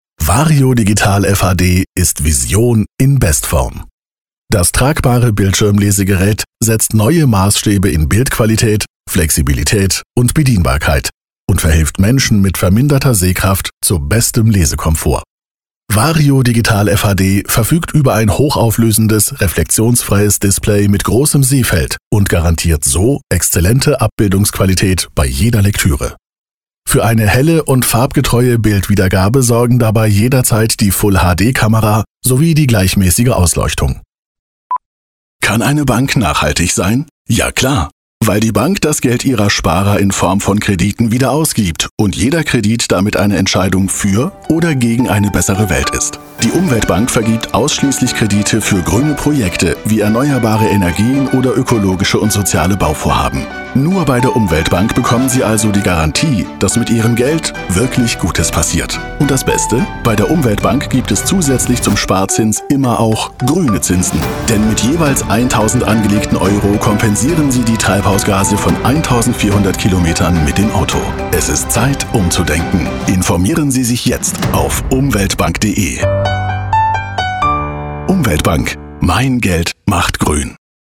Male
Approachable, Assured, Bright, Character, Confident, Conversational, Cool, Corporate, Deep, Energetic, Engaging, Friendly, Funny, Natural, Sarcastic, Smooth, Soft, Upbeat, Versatile, Warm
Microphone: Neumann TLM 103